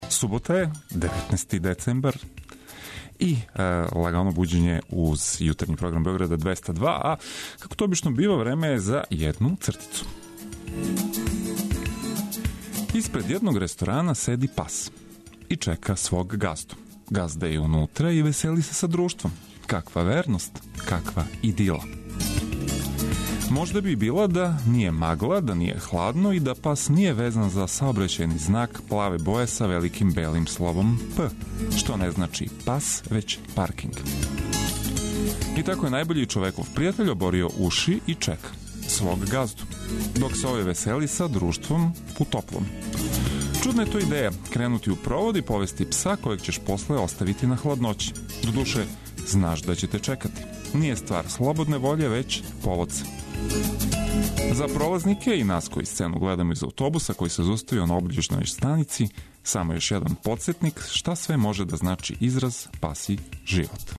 Водитељ: